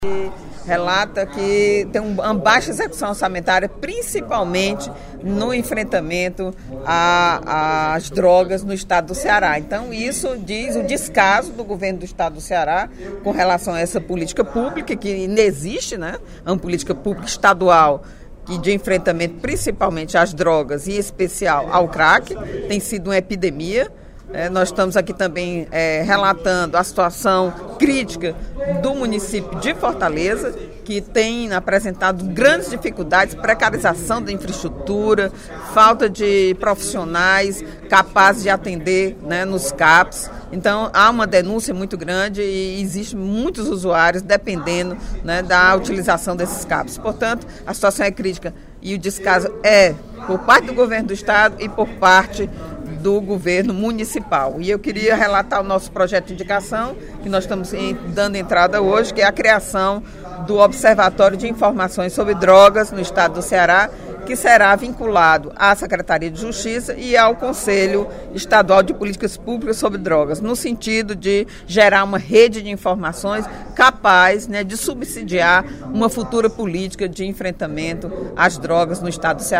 A deputada Eliane Novais (PSB) informou, durante o primeiro expediente da sessão plenária desta quarta-feira (25/06), que irá propor, por meio de projeto de indicação, a criação do Observatório de Informações sobre Drogas do Estado do Ceará.
Em aparte, os deputados Heitor Férrer (PDT), Professor Pinheiro (PT) e Antonio Carlos (PT), parabenizaram Eliane pela idéia do Observatório sobre Drogas.